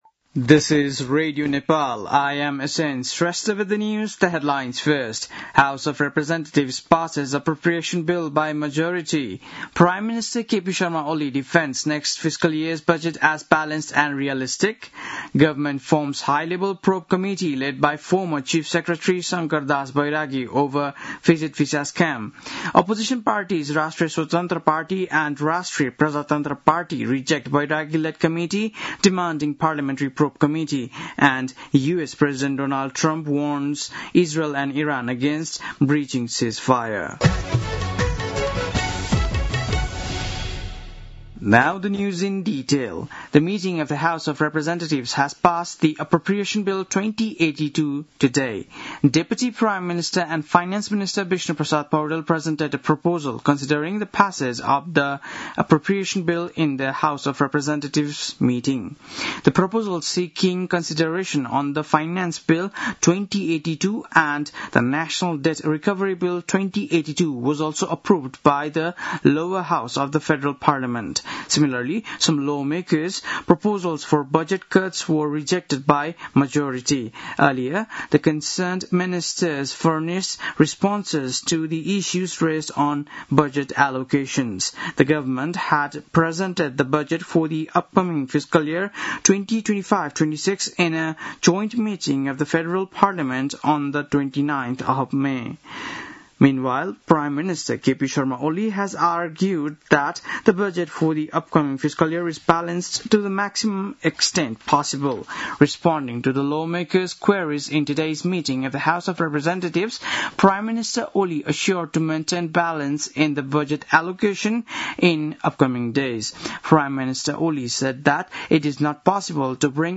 बेलुकी ८ बजेको अङ्ग्रेजी समाचार : १० असार , २०८२